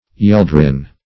Search Result for " yeldrin" : The Collaborative International Dictionary of English v.0.48: Yeldrin \Yel"drin\or Yeldrine \Yel"drine\, n. [Cf. Yellow .]